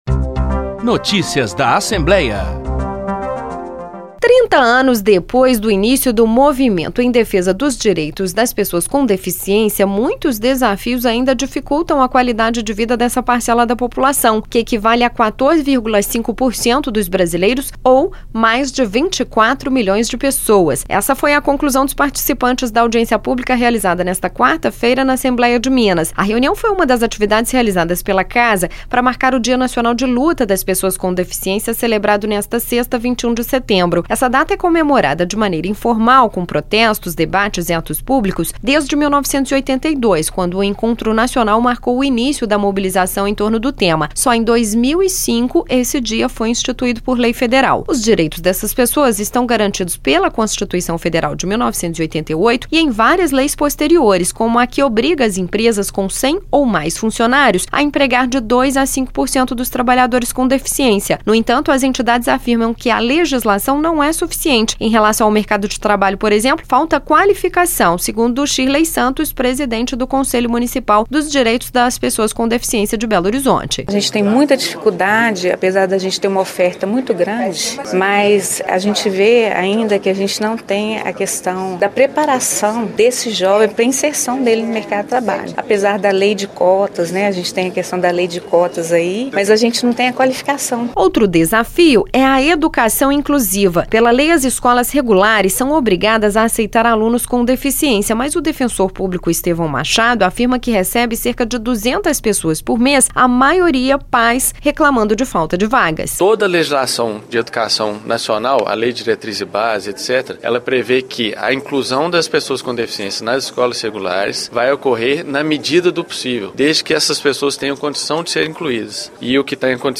O histórico de luta das pessoas com deficiência foi discutido em audiência pública nesta quarta-feira (19)